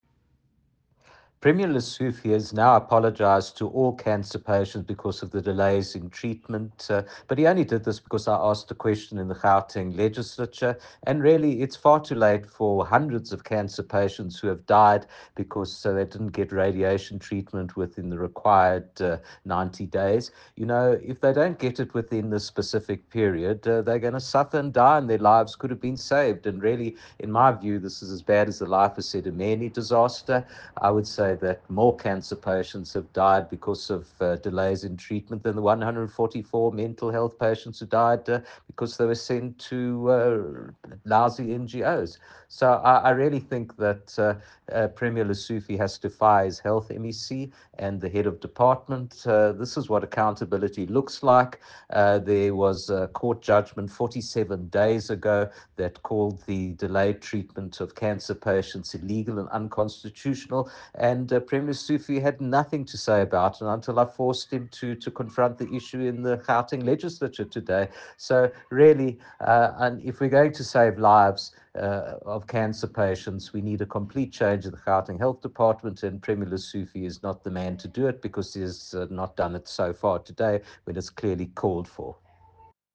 soundbite by Jack Bloom MPL.